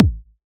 edm-kick-55.wav